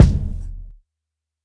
Kicks
lis_kick.wav